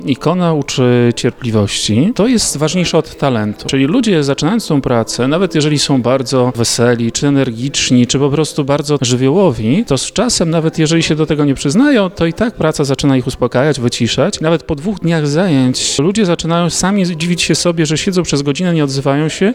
Ikonograf o pisaniu ikon.mp3